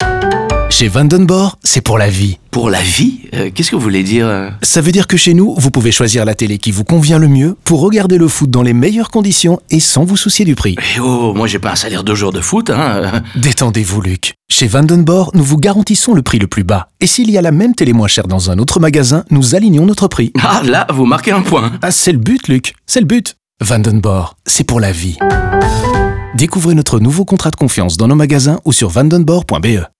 La voix off entame ainsi à chaque fois la conversation avec différents clients et répond à toutes leurs questions.
Enfin, notons également que le logo sonore a lui aussi été légèrement modifié, même si la célèbre mélodie reste inchangée et résonne probablement déjà dans votre tête.
Vanden Borre-Radio-Prix.wav